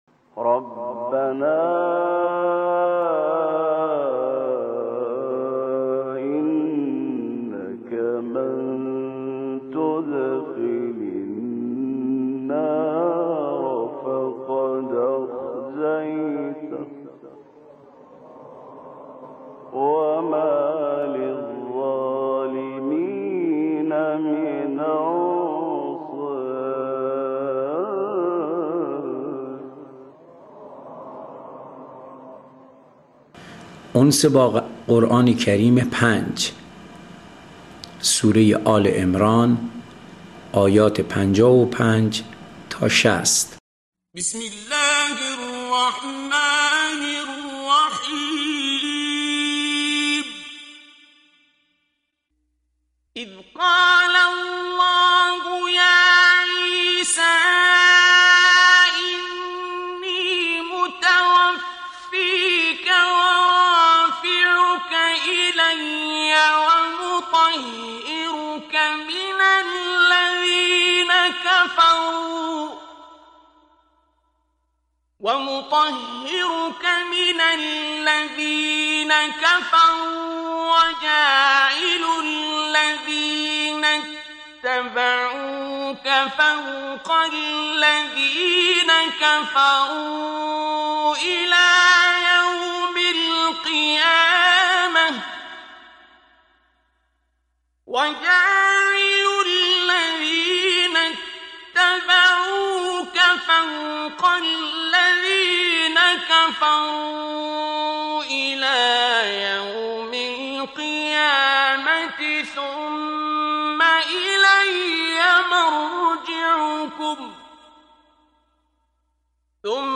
قرائت آیات آیات 55 الی 59 سوره آل عمران